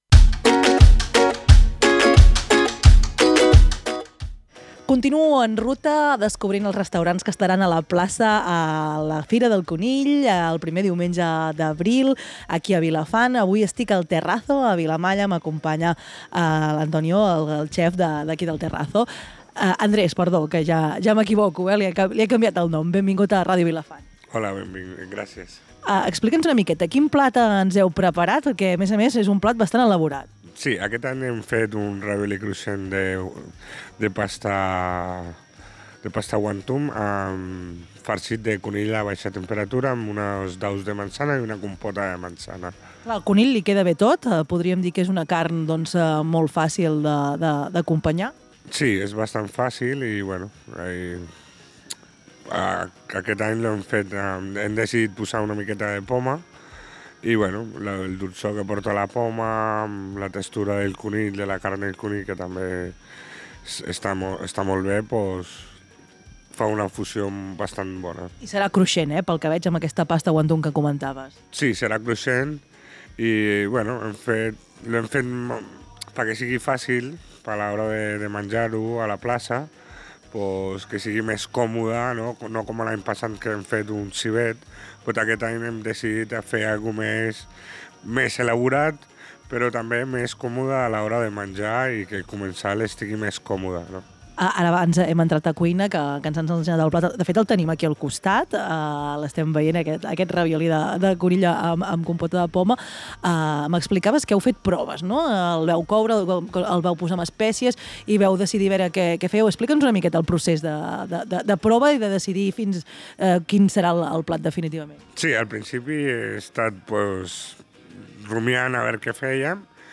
En directe